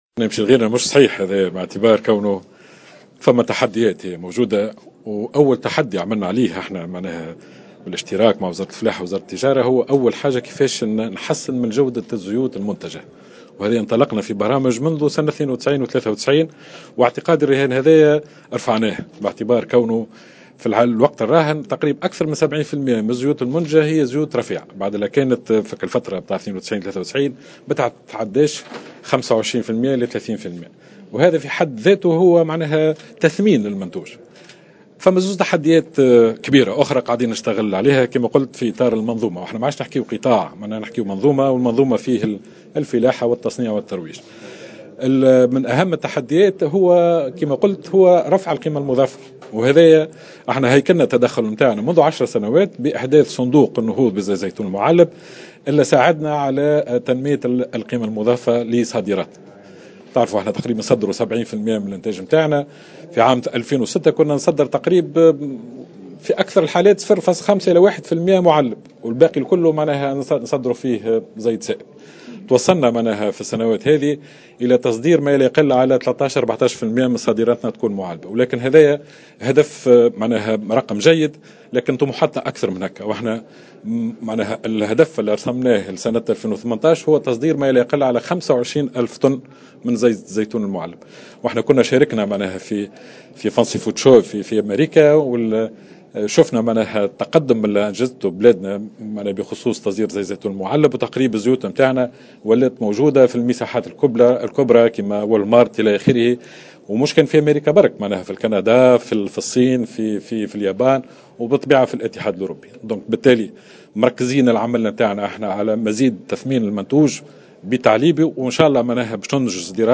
قال وزير الصناعة زكريا حمد في تصريح لمراسلة الجوهرة "اف ام" اليوم الإثنين 11 جويلية 2016 على هامش أشغال المجلس الدولي للزيتون أن تونس تواجه عدة تحديات في ما يتعلق بمنظومة زيت الزيتون المعلب والموجه للتصدير وأولها كيفية تحسين جودة الزيوت المنتجة.